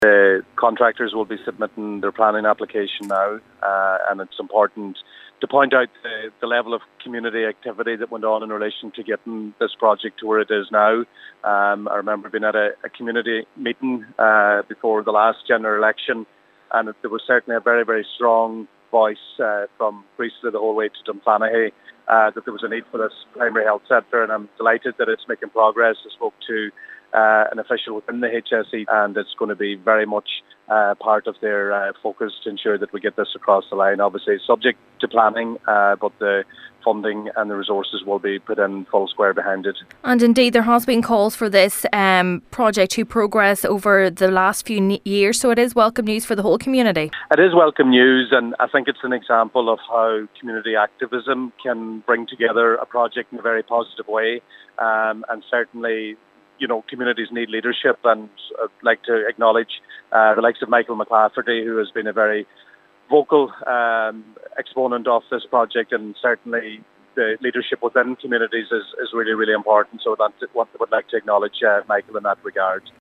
Minister Joe McHugh has praised the local community for their efforts in keeping the project on the table: